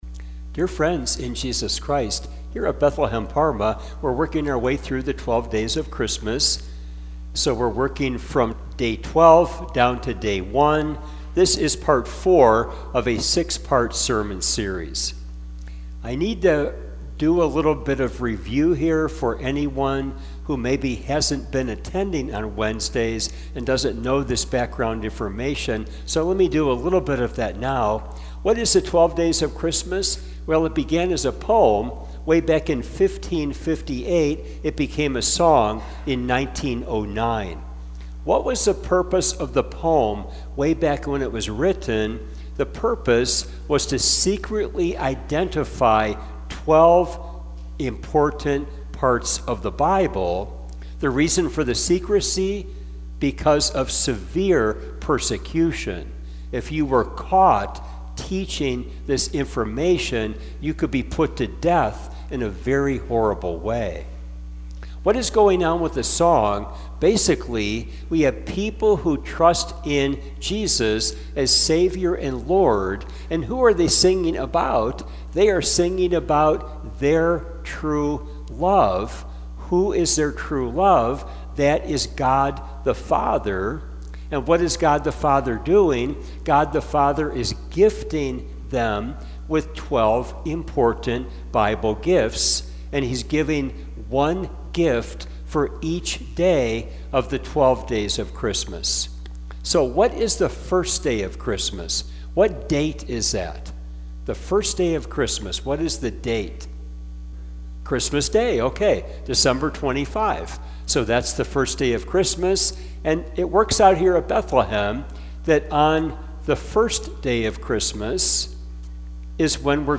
The 12 days connected with 12 important parts of the Bible - watch, listen or read to benefit! (This video is our Saturday service.)